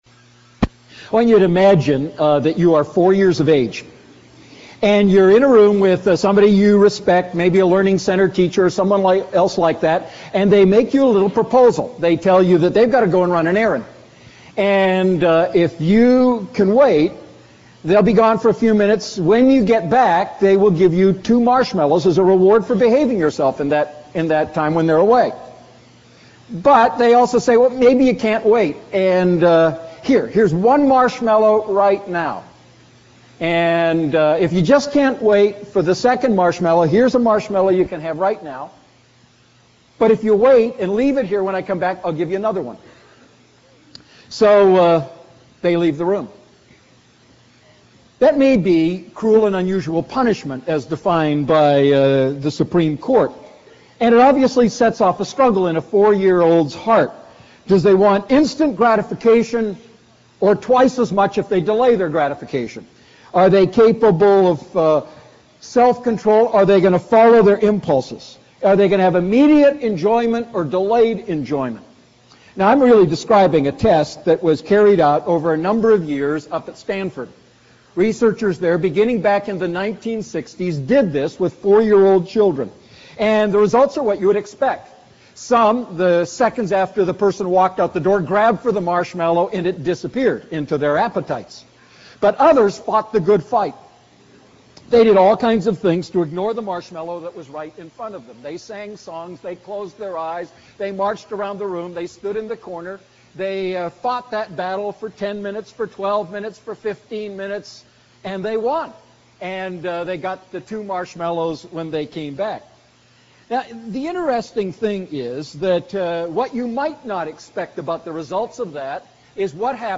A message from the series "Family Matters."